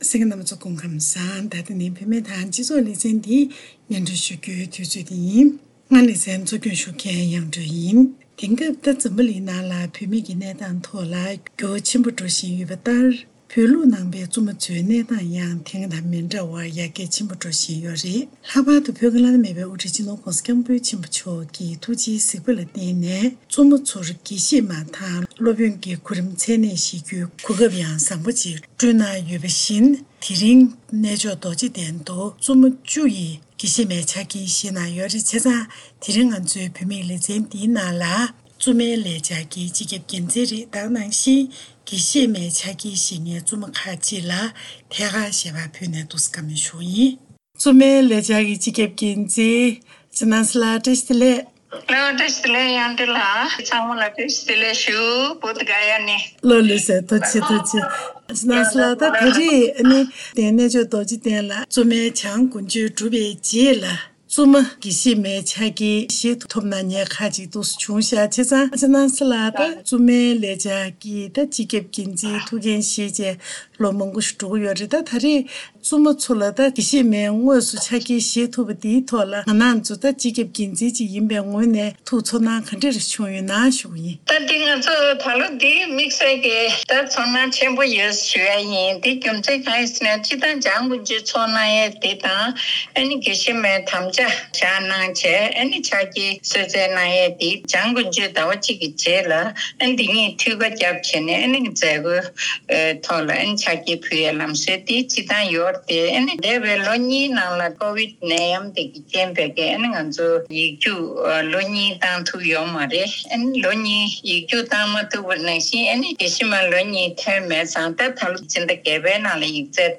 ཐད་ཀར་འབྲེལ་ཡོད་ཁག་ལ་ཞལ་པར་བརྒྱུད་བཅར་འདྲི་ཞུས་པ་ཞིག་གསན་རོགས་གནང་།